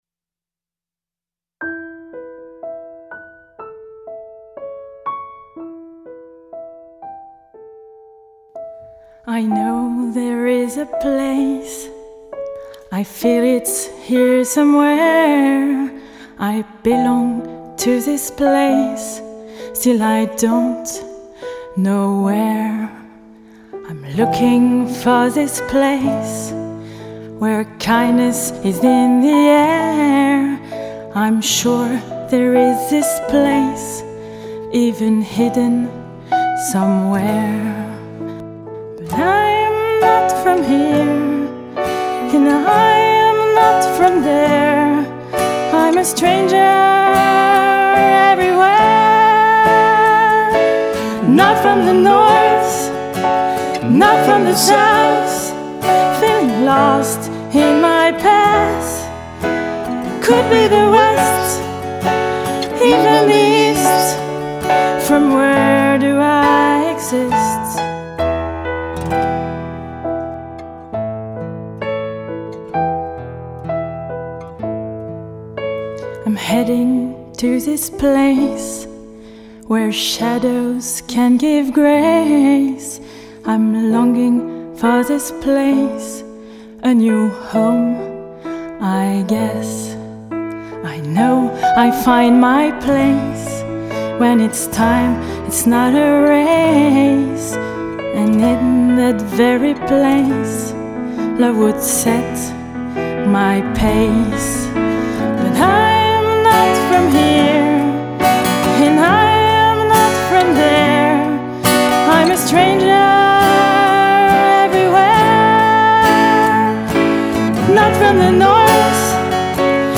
The songs were written and recorded under a tight time schedule, so these are just workshop recordings.